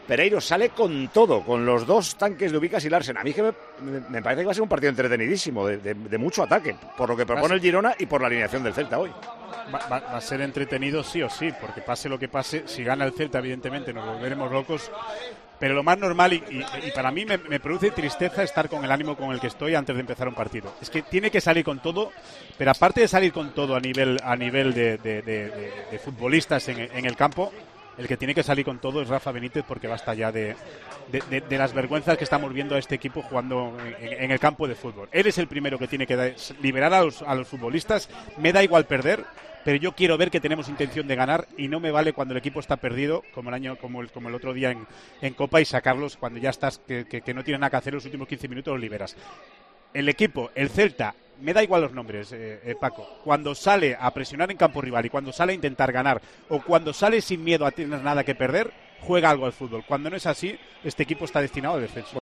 Óscar Pereiro ya lanzaba un aviso al Celta en la presentación del encuentro en Tiempo de Juego: "Me da igual los nombres, cuando sale a presionar en campo rival, y sale a intentar ganar, juega algo al fútbol, cuando no es así este equipo está destinado al descenso".